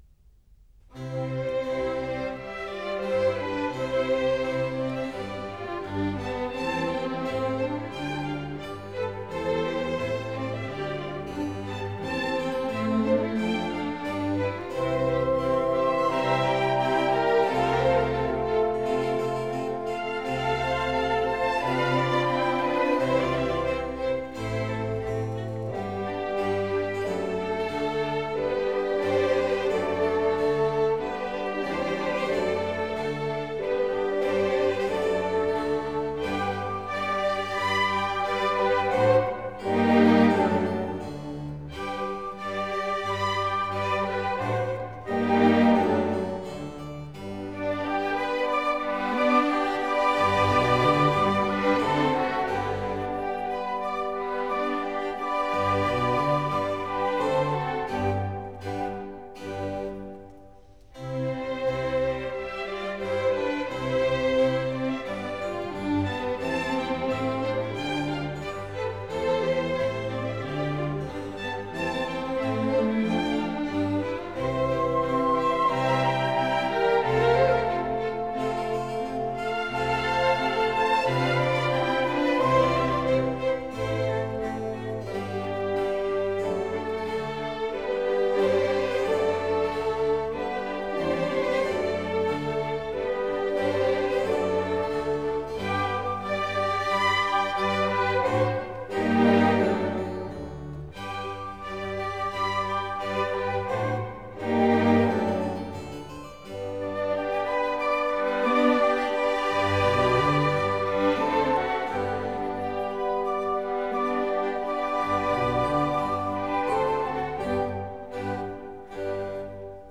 » nhac-khong-loi